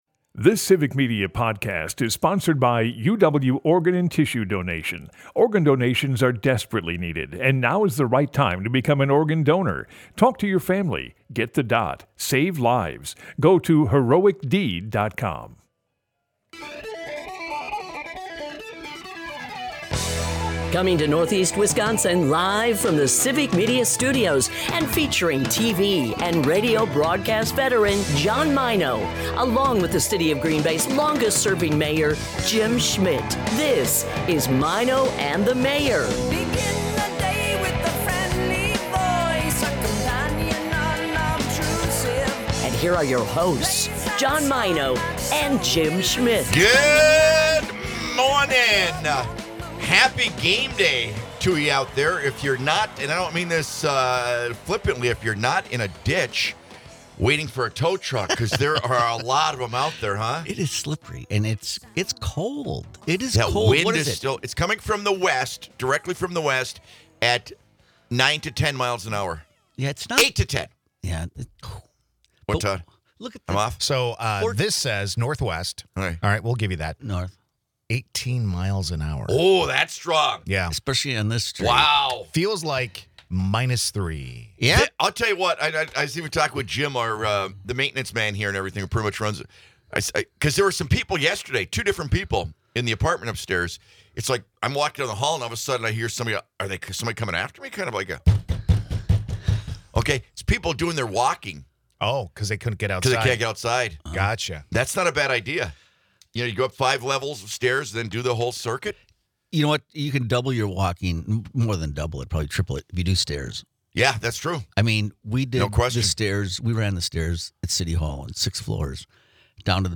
The guys start the morning by talking about the crazy winter weather we all experienced yesterday.